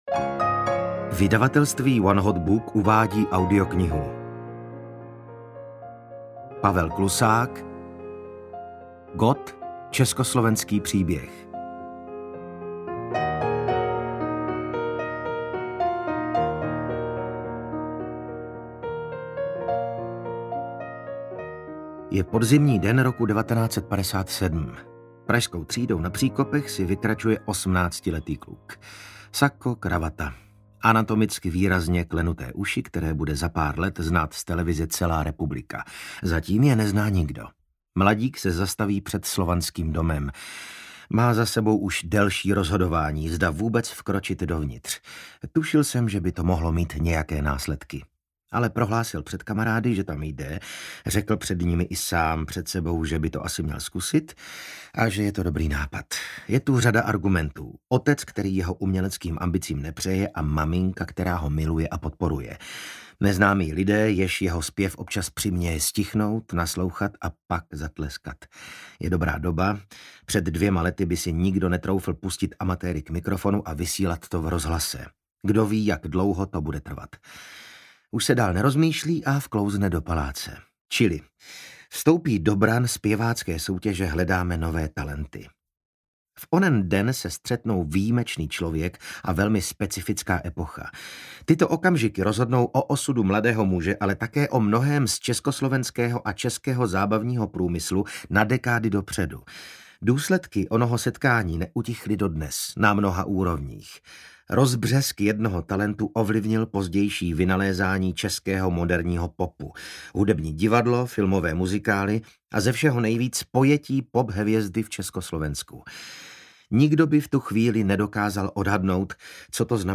Ukázka z knihy
gott-ceskoslovensky-pribeh-audiokniha